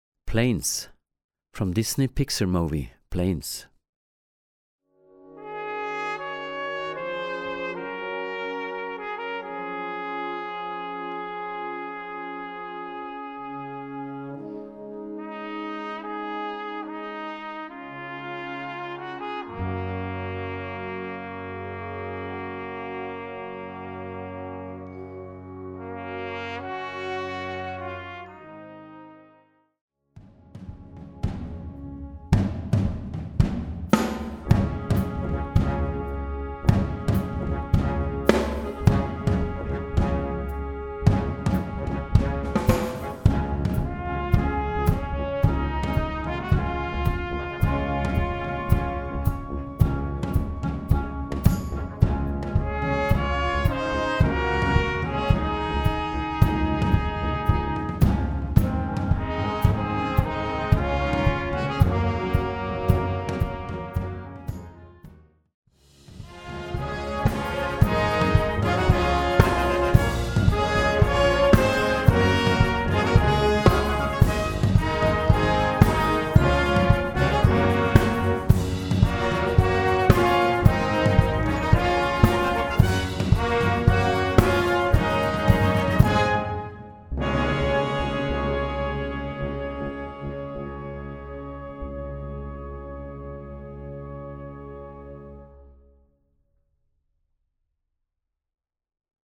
Blasorchester PDF